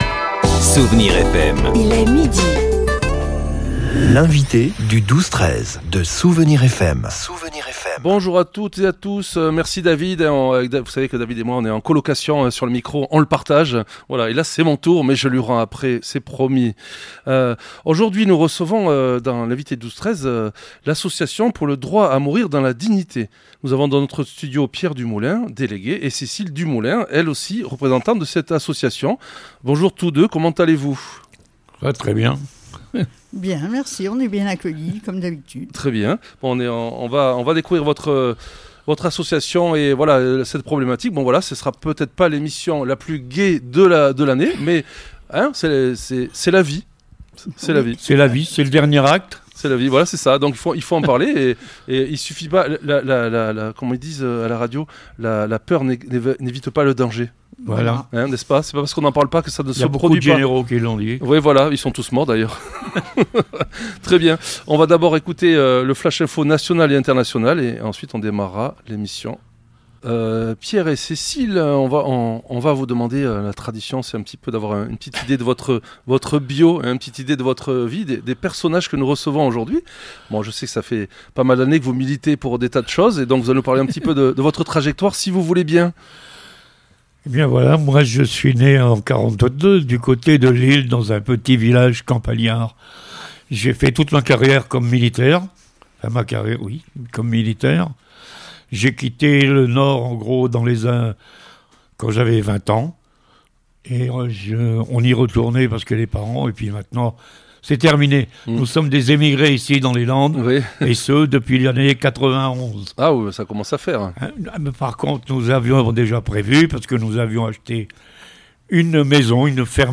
L'invité(e) du 12-13 recevait aujourd'hui ADMD40, l’association pour le droit à mourir dans la dignité.